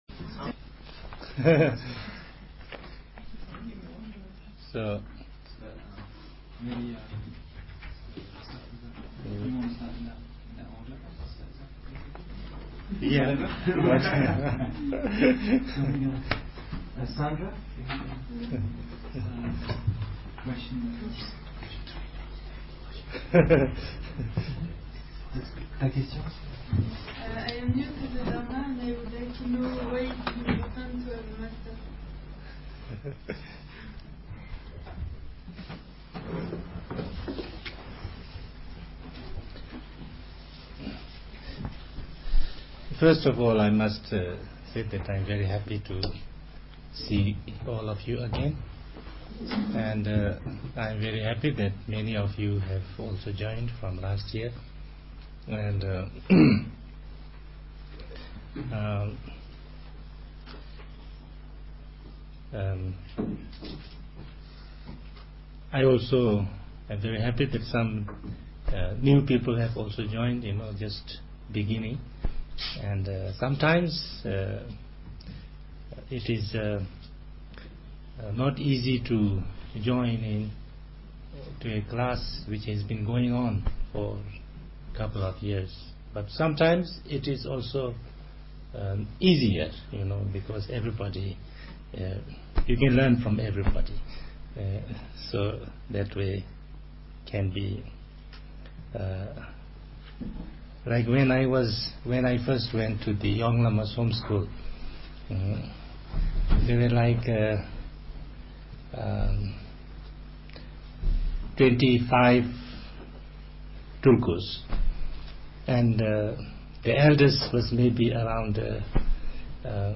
The questions asked to Ringu Tulku Rinpoche are listed below.